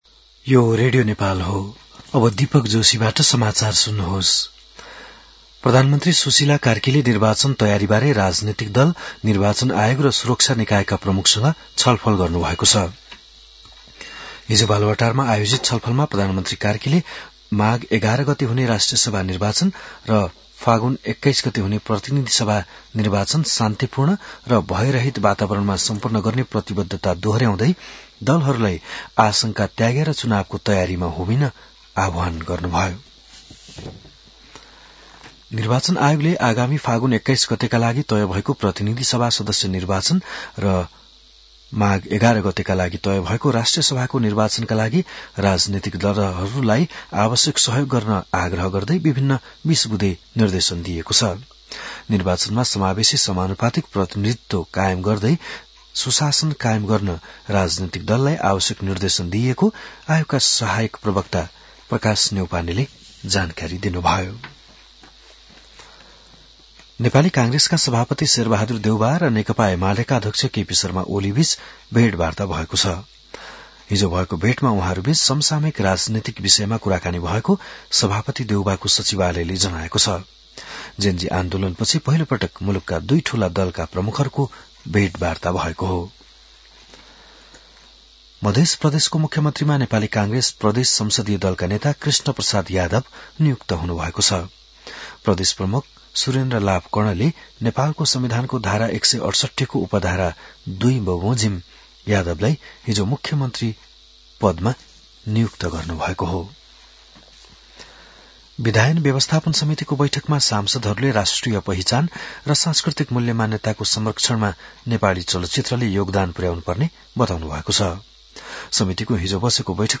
बिहान १० बजेको नेपाली समाचार : २० मंसिर , २०८२